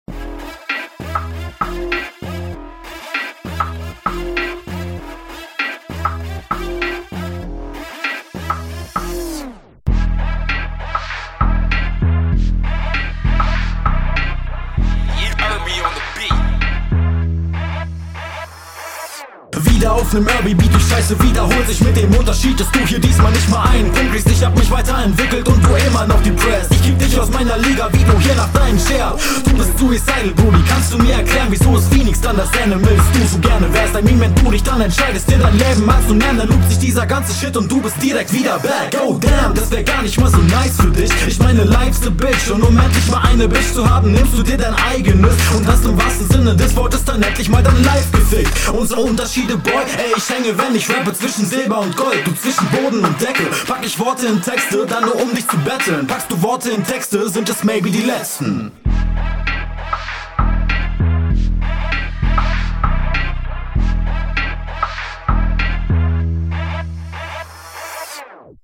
Coole Runde, der Beat ist richtig nice und extra props für die angenehme Rundenlänge